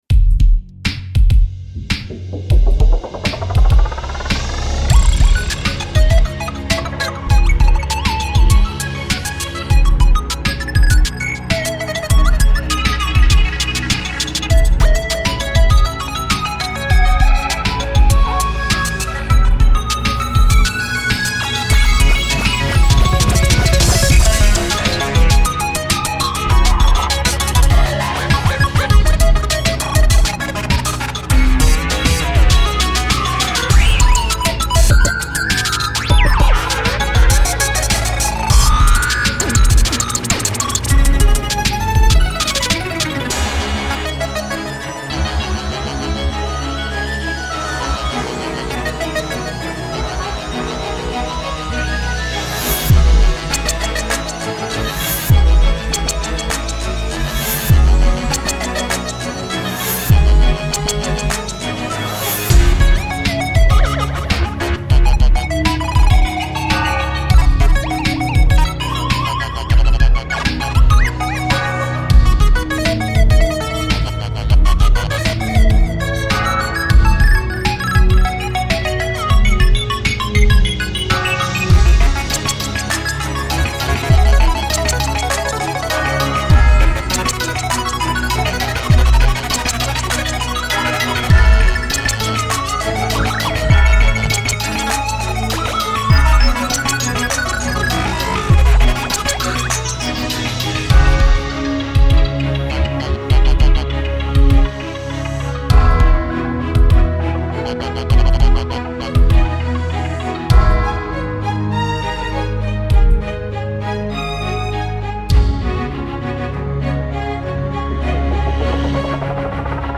Chipcore